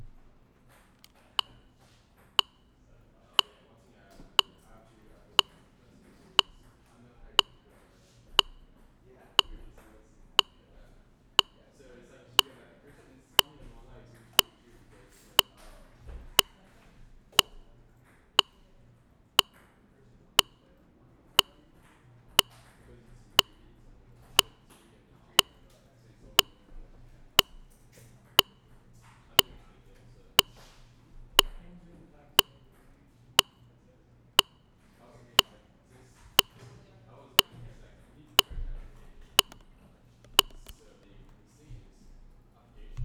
One second Impulses.wav